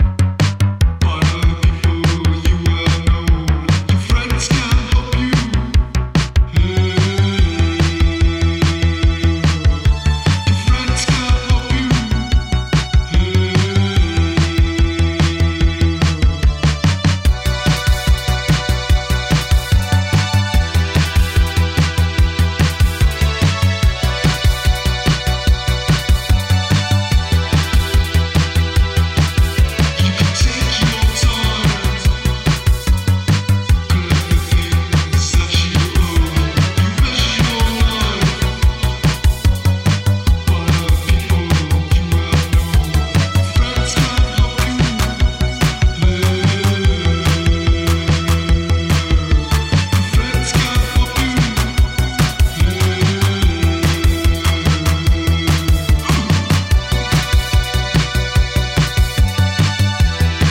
Dubby techno tracks on stamped white label edition.